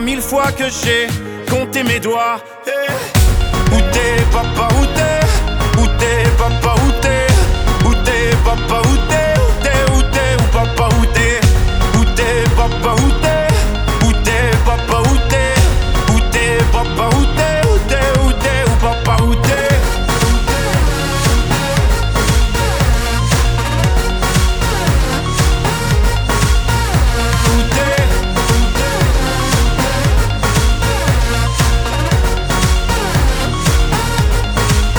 Жанр: Поп
# French Pop